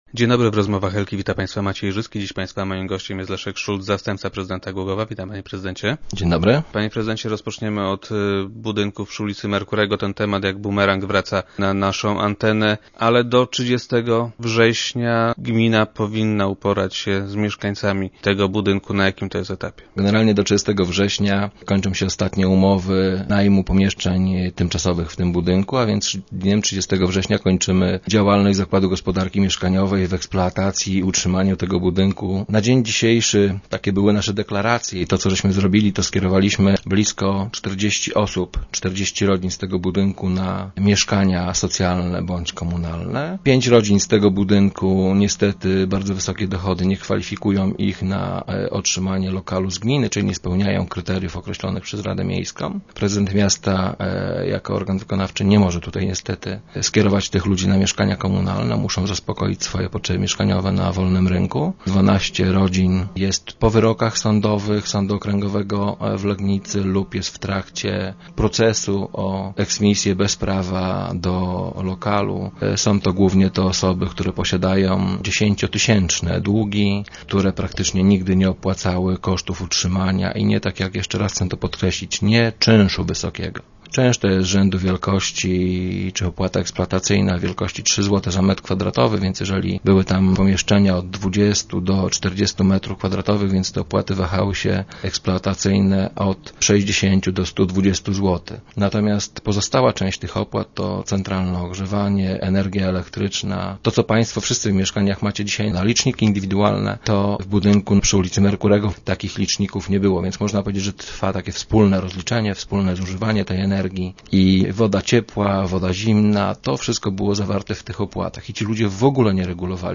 - Zrealizowana zostanie jednak tylko jednam z tych inwestycji - poinformował Leszek Szulc, zastępca prezydenta Głogowa, który był dziś gościem Rozmów Elki.